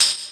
Tambourine_3.wav